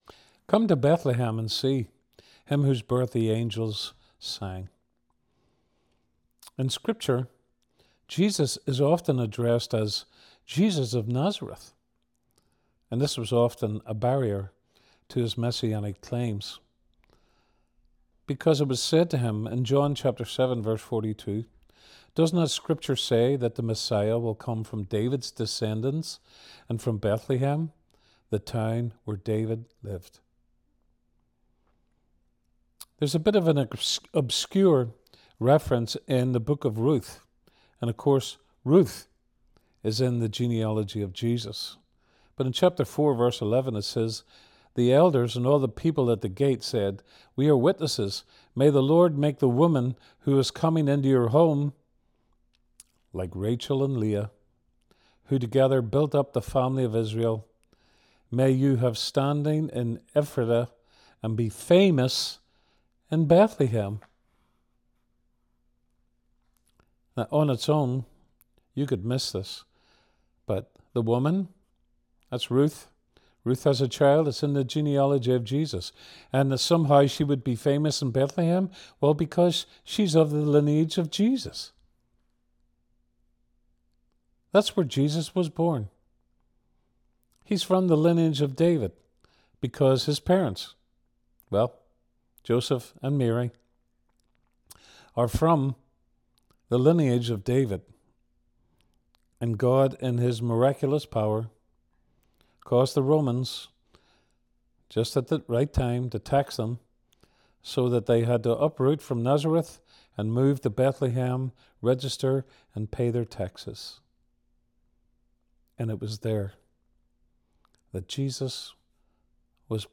A Christmas devotional